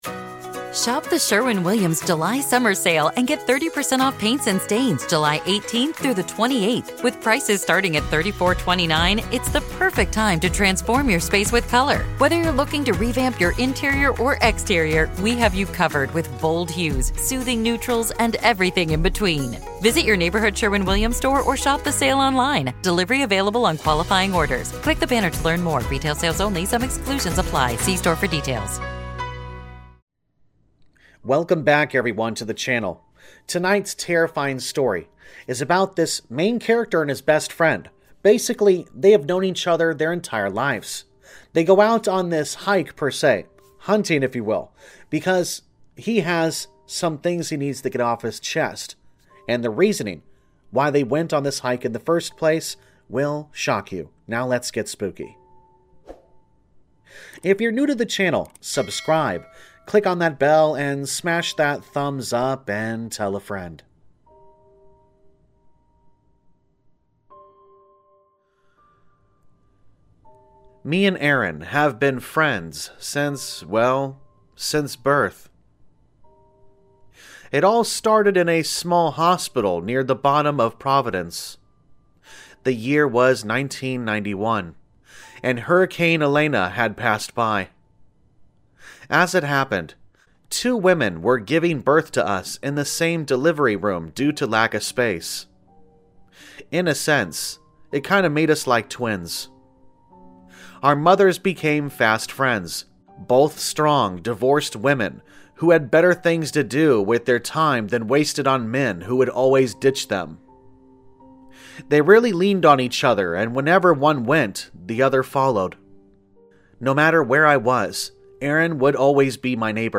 Sound Effects Credits
All Stories are read with full permission from the authors